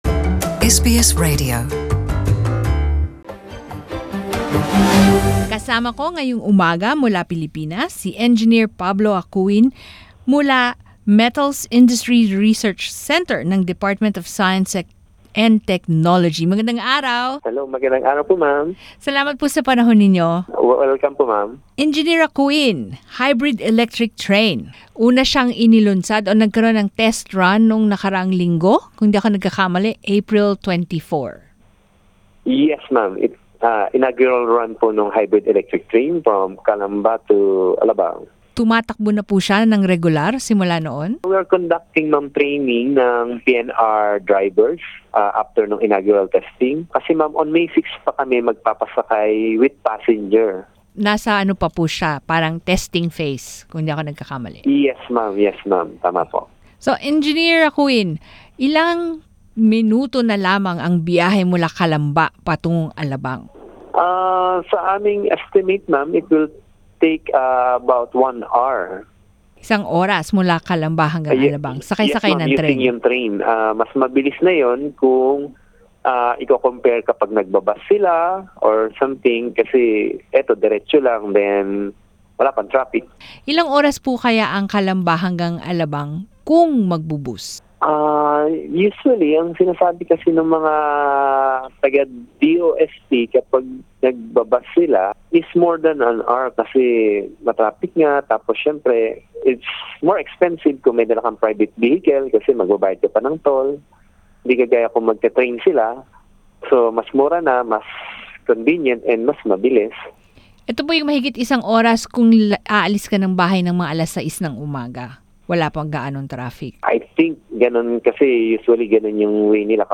Narito ang panayam